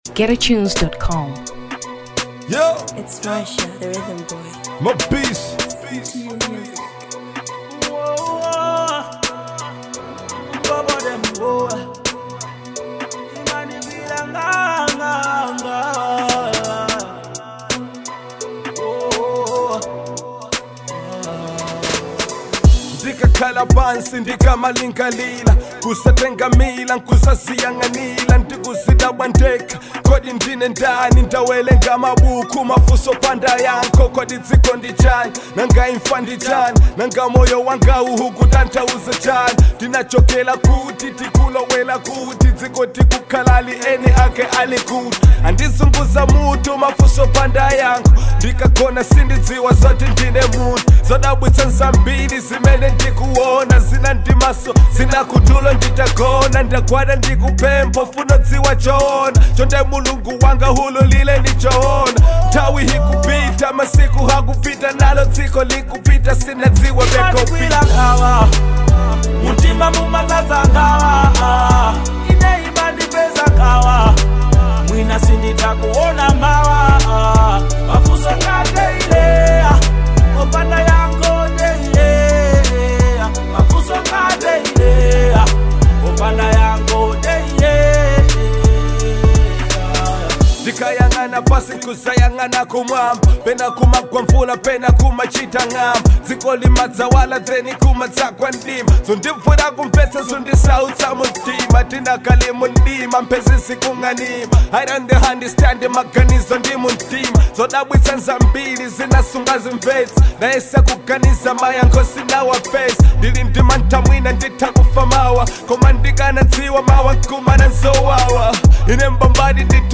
Hip Hop 2023 Malawi